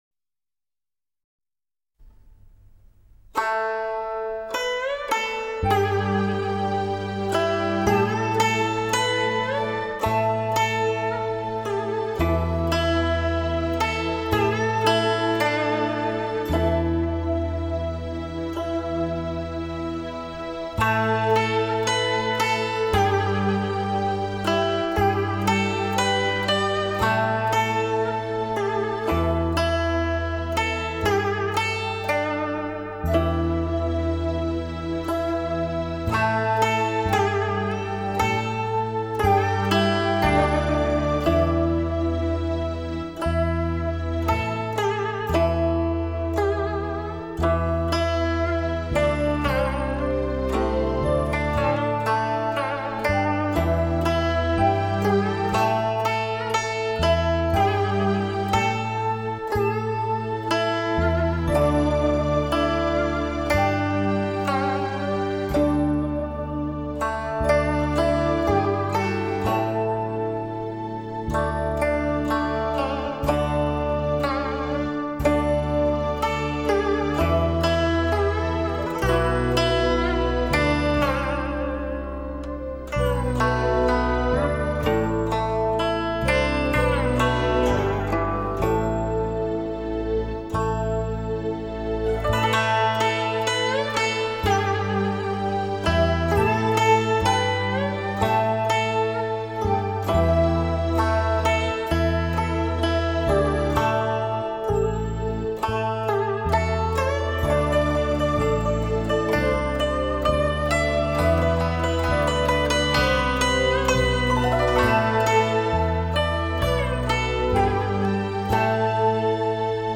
DTS其清脆、纯净、高清晰、具有音乐包围感，